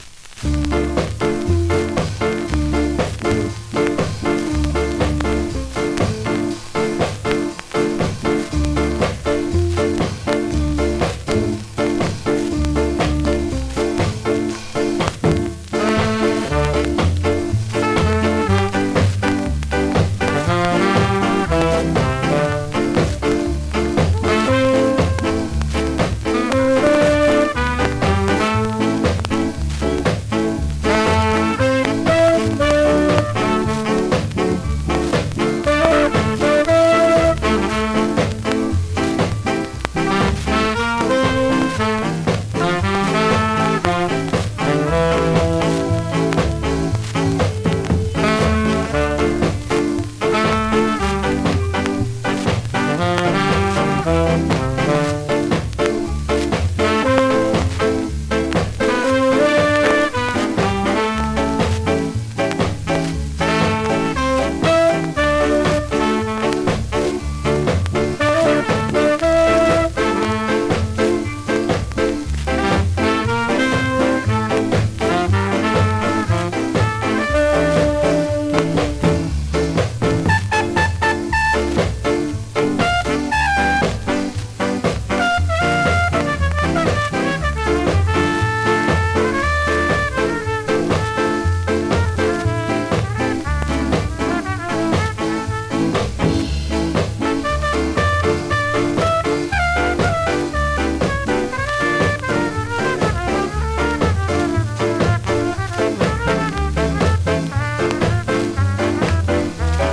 ヒビの所テープ補修してます)   コメントキラーSKA!!
スリキズ、ノイズそこそこありますが